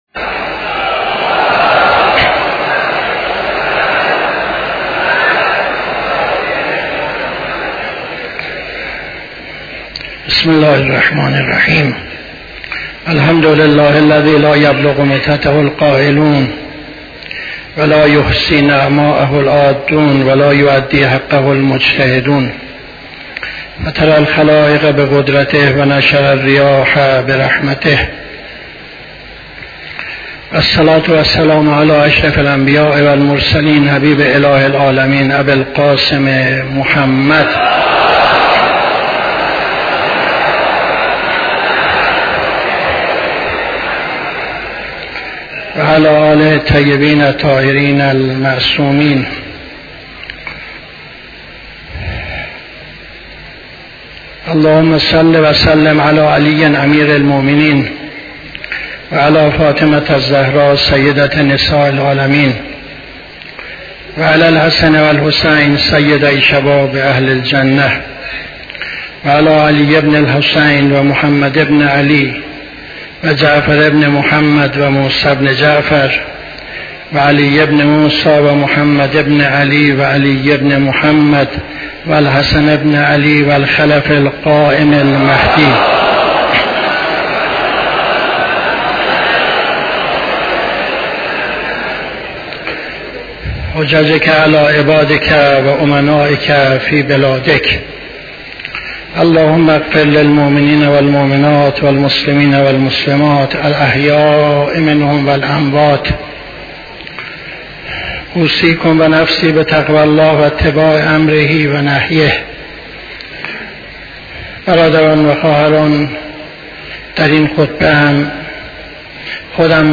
خطبه دوم نماز جمعه 08-08-77